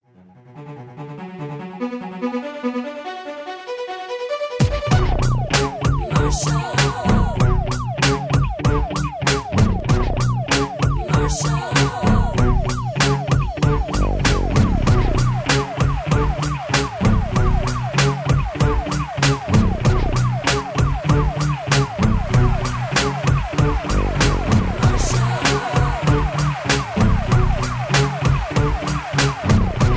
rap, r and b, dance music, backing tracks